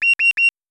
warning_immediate.wav